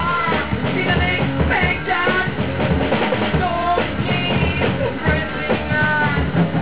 El grup està format per bateria, baix, guitarra i veu.